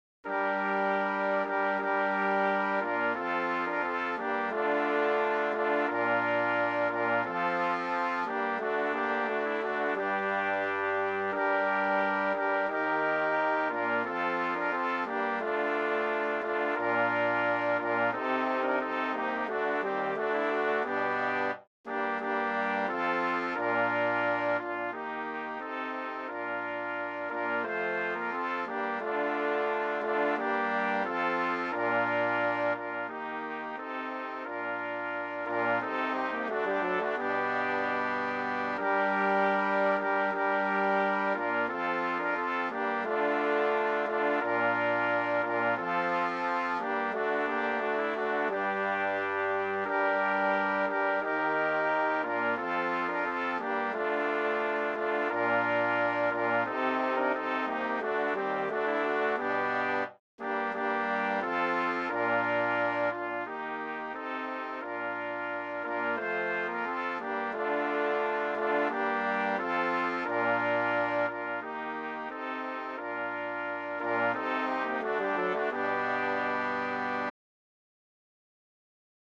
MADRIGAL
FOR STANDARD BRASS QUINTET